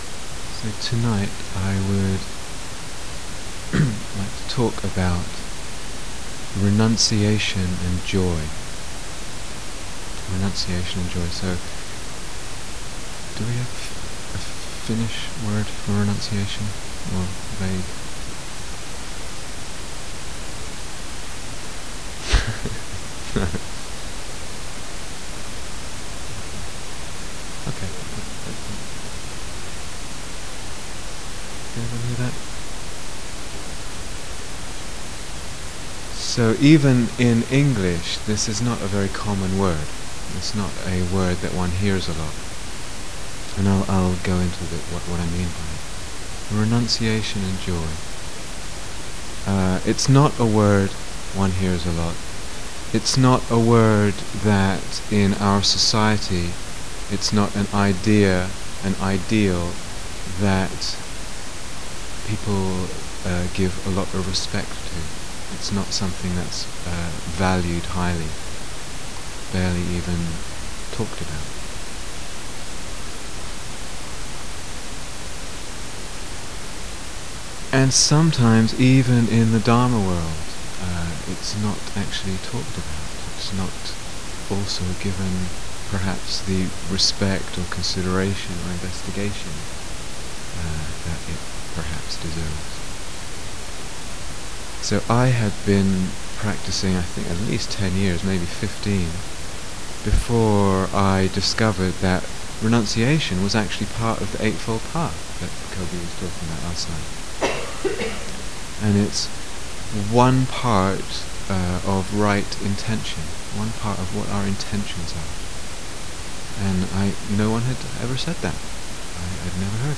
Renunciation and Joy Download 0:00:00 --:-- Date 1st October 2006 Retreat/Series Silent Autumn Retreat, Finland 2006 Transcription So tonight I would like to talk about renunciation and joy.